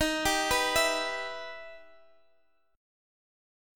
Em/D# Chord